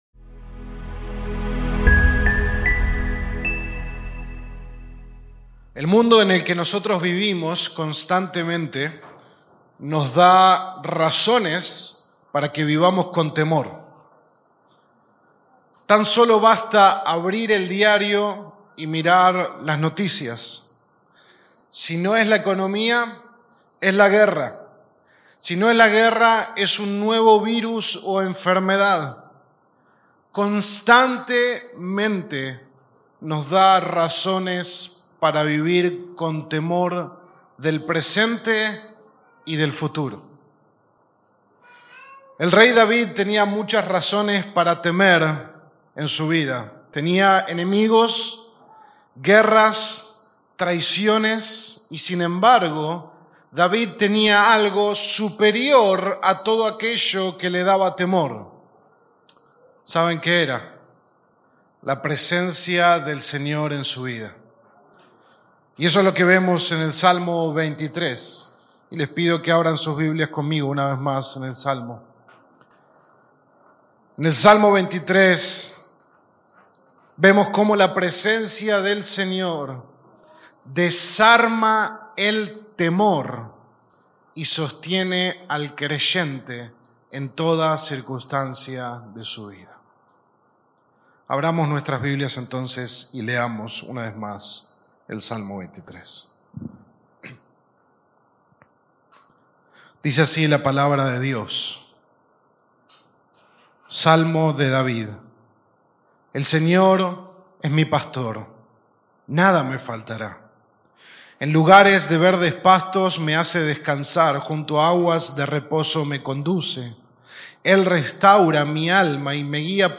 Sermón 3 de 8 en Delante de Dios